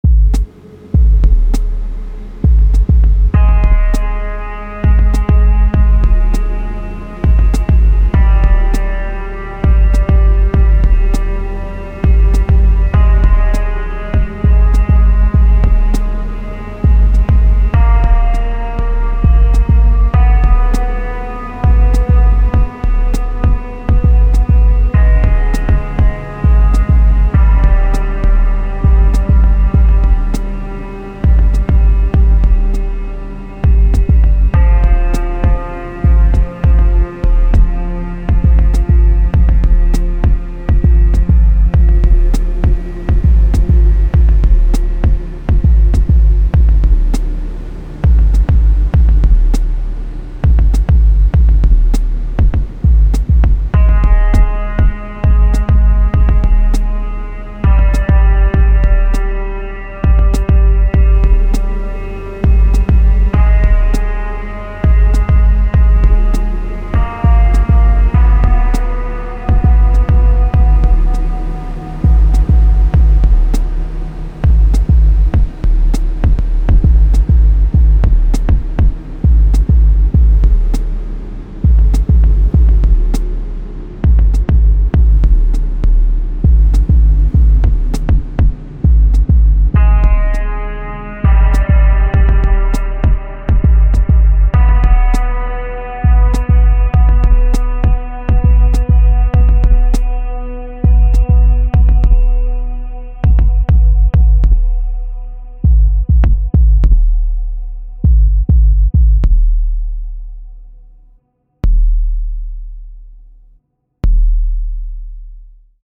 Music examples
(* mastered for small phone speaker / !!!! very loud !!!!)